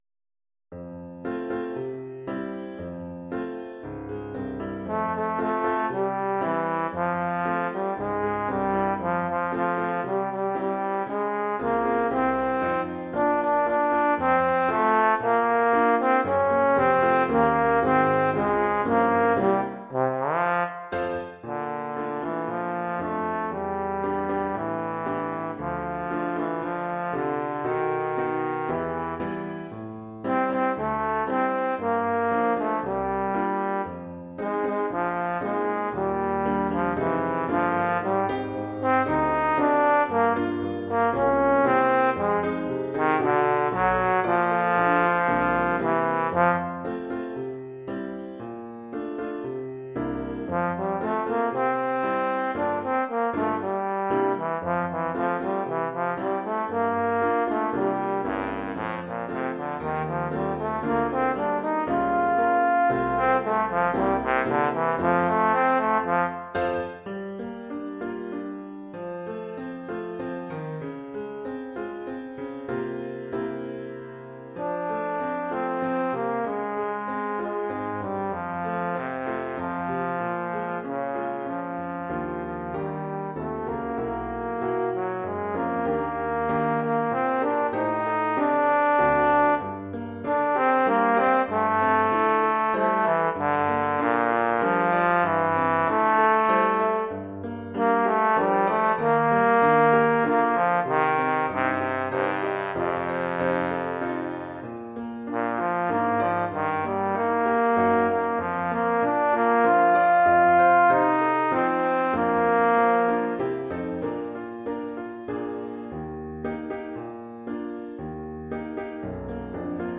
Oeuvre pour trombone et piano.